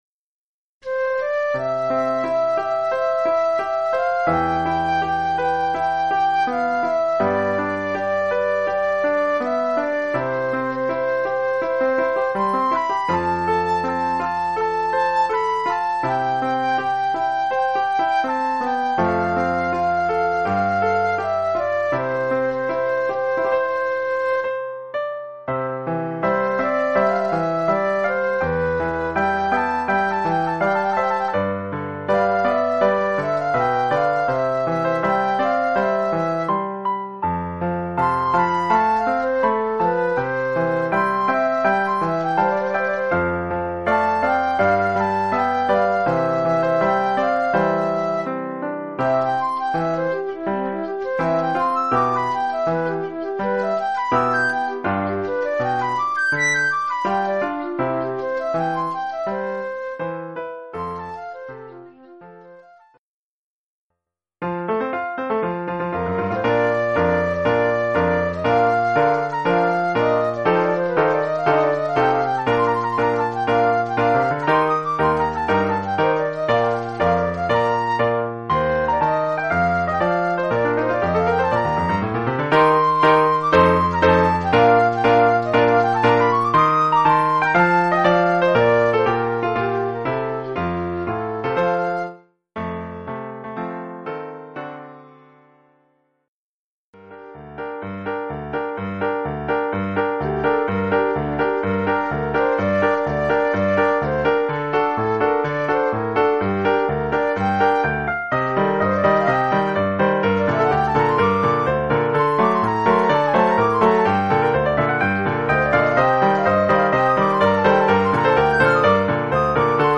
1 titre, flûte et piano : conducteur et partie de flûte
Oeuvre pour flûte et piano.